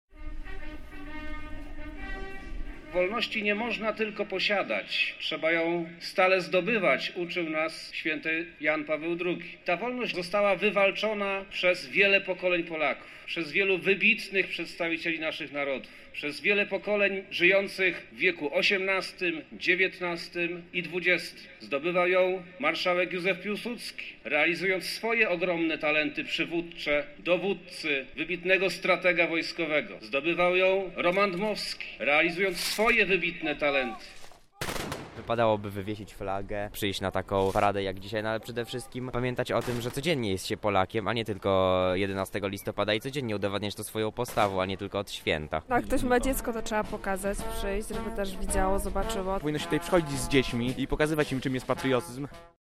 Na Placu Zamkowym pojawiły się tłumy by wspólnie obchodzić Święto Niepodległości
Hymn państwowy, wciągnięcie flagi na maszt i złożenia kwiatów pod pomnikami bohaterów. Na tegorocznych uroczystościach odbyły się również pokazy sprzętu wojskowego, defilada oraz koncert utworów patriotycznych w wykonaniu orkiestry wojskowej.